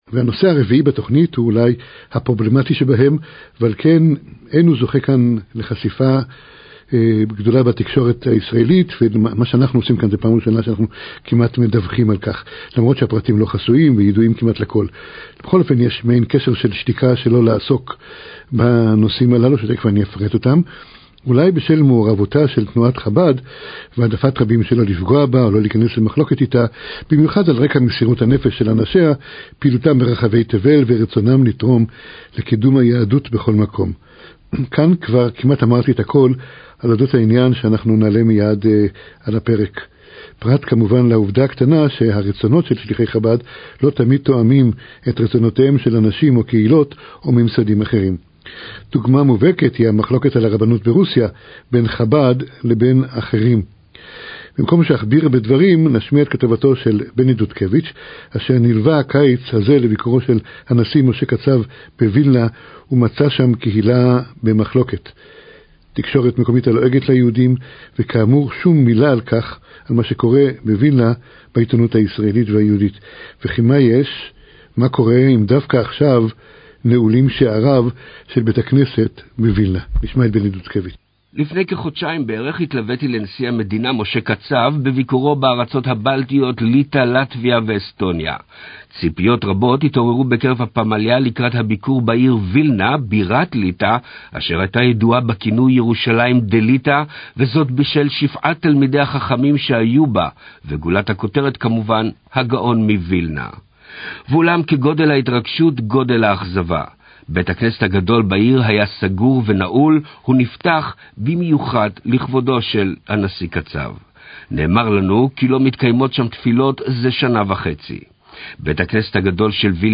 משדר מגמתי בקול ישראל על שלוחי חב"ד